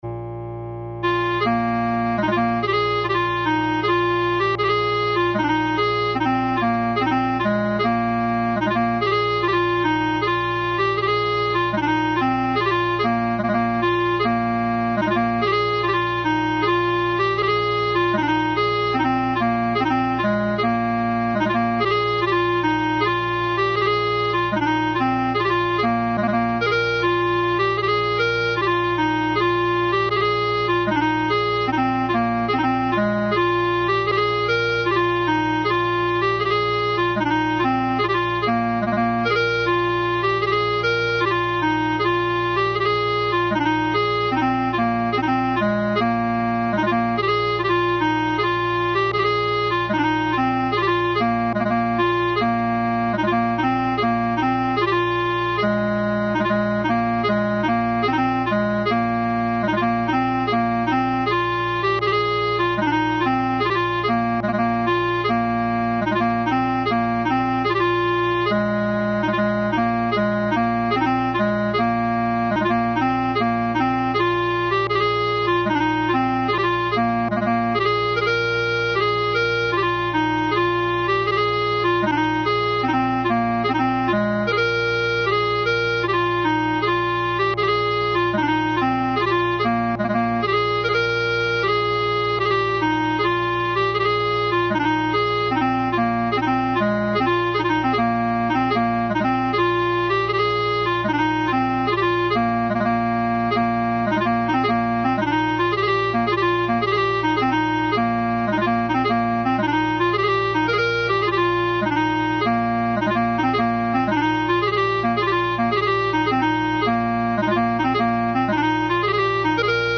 March 80 bpm
Strathspey 112 bpm
Reel 84 bpm